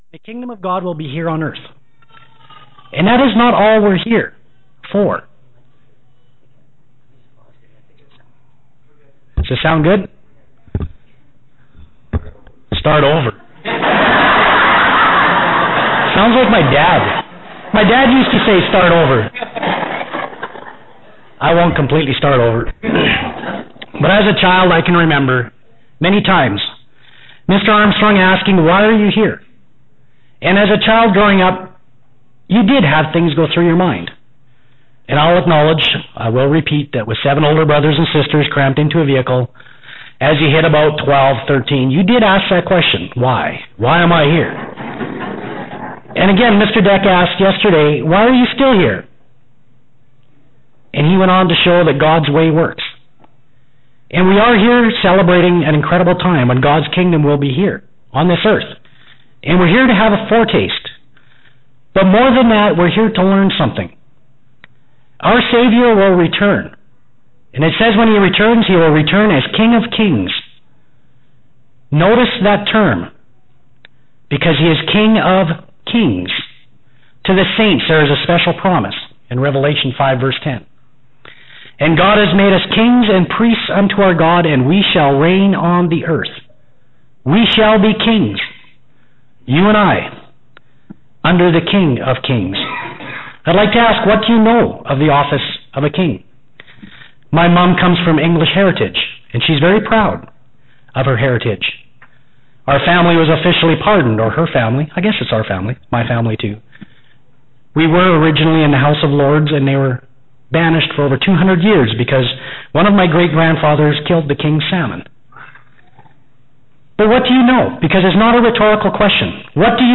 This sermon was given at the Canmore, Alberta 2012 Feast site.